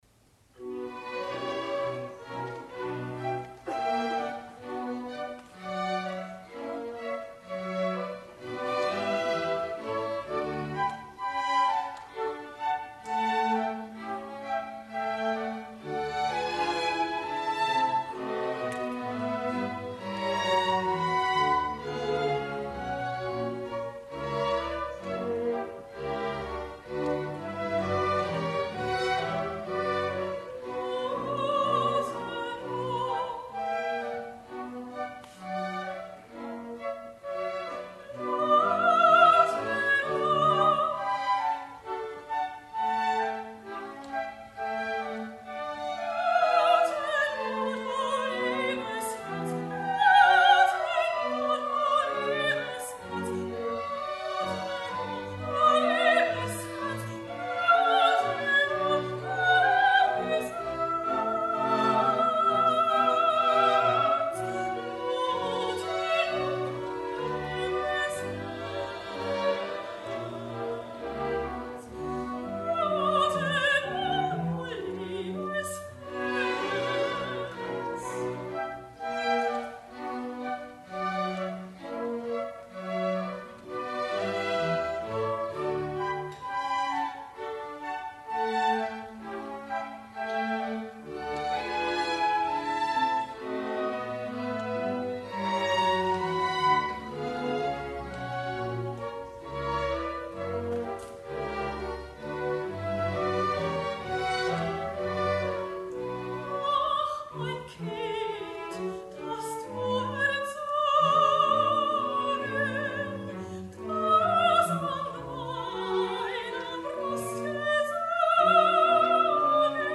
12. Arie (Sopran): Blute nur, du liebes Herz!
Matthäus-Passion von Solisten, Chor und Orchester des Collegium musicum
12-arie-sopran-blute-nur-du-liebes-herz